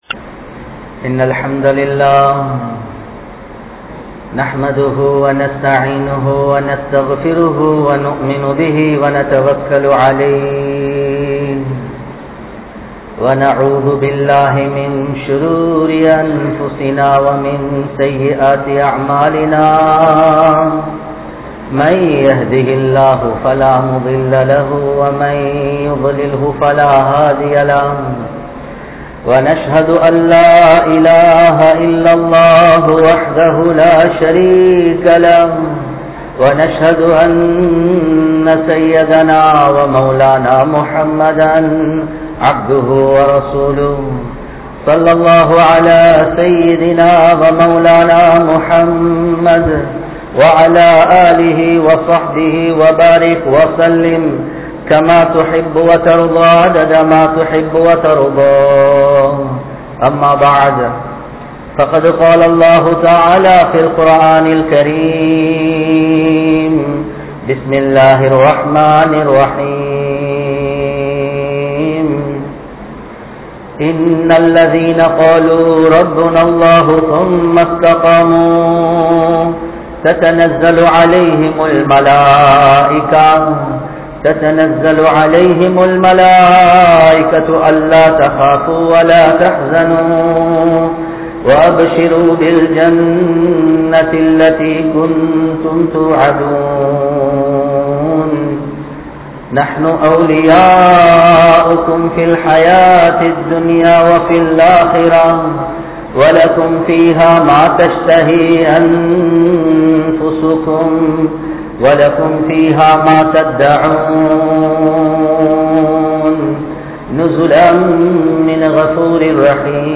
Aniyaayam Ilaikap Pattavarhal | Audio Bayans | All Ceylon Muslim Youth Community | Addalaichenai